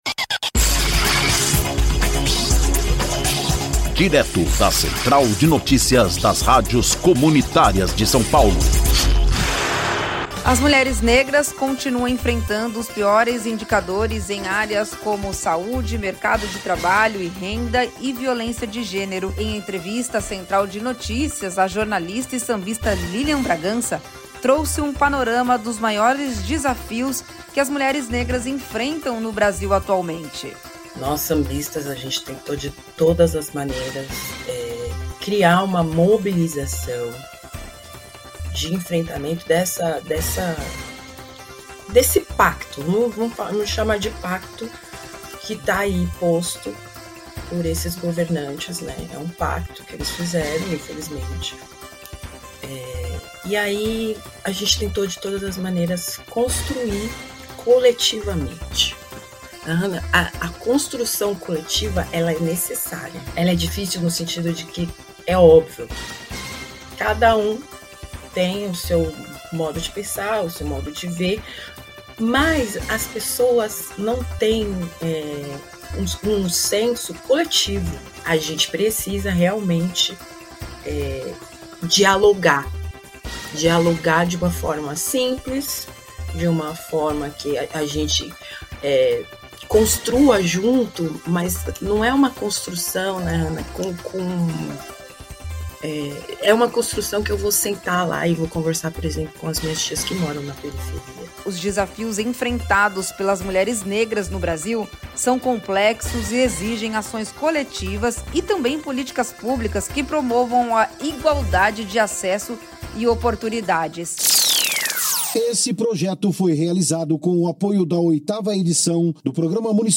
Em entrevista à Central de Notícias